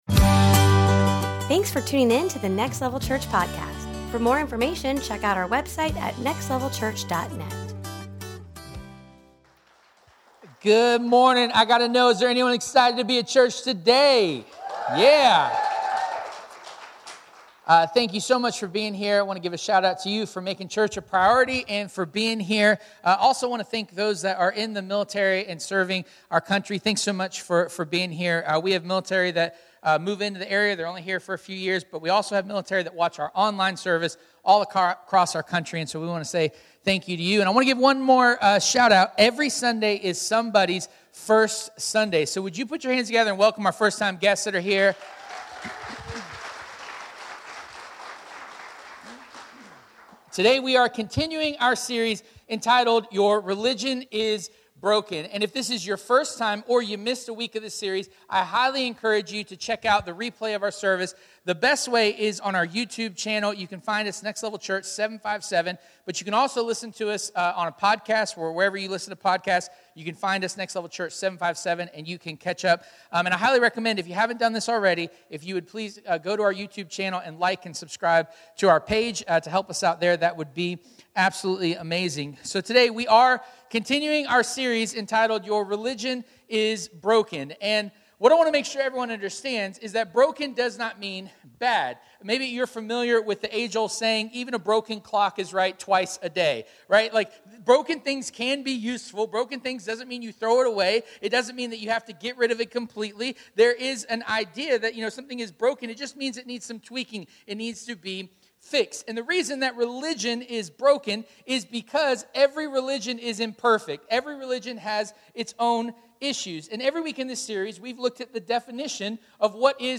Your Religion is Broken Service Type: Sunday Morning « Your Religion is Broken